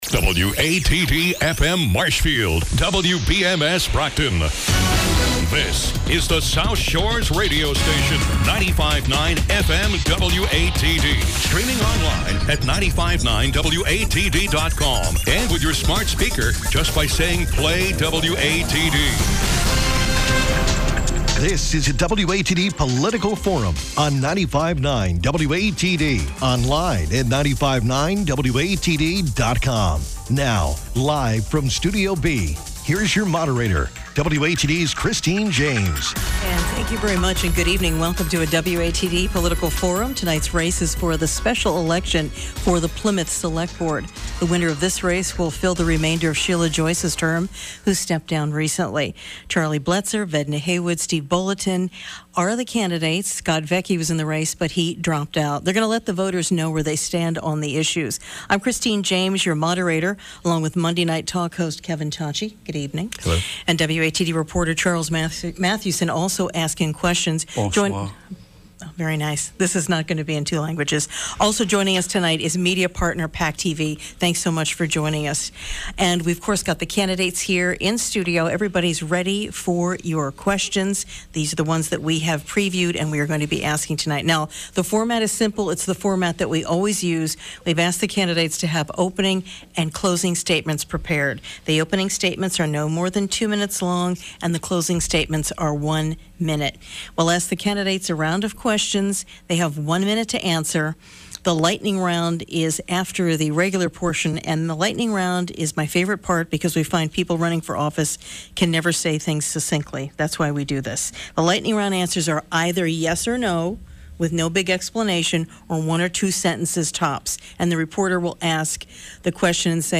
WATD hosted a political forum featuring the special election race for the Plymouth Select Board.